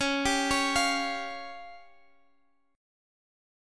Listen to DbMb5 strummed